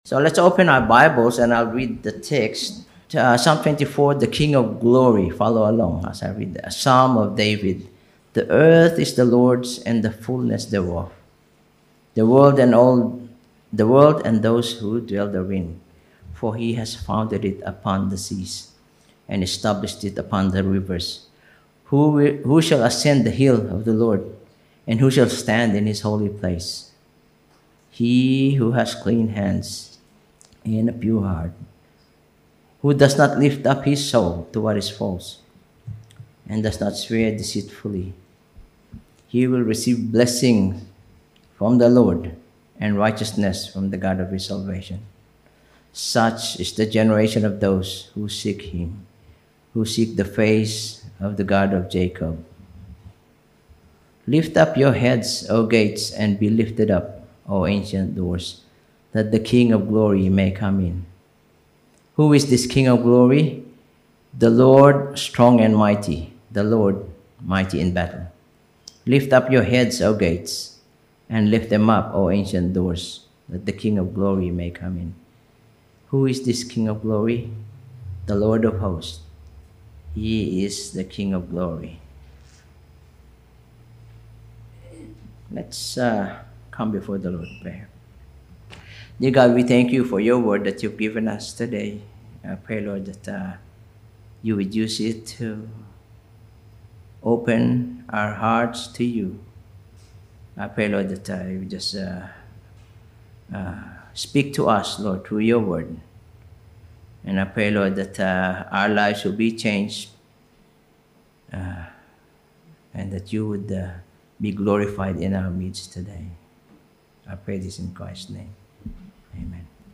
Topical Sermon Passage: Psalm 24:1-10 Service Type: Sunday Morning « Jesus Our Perfect Saviour Part 1 of 2 Hope for the Living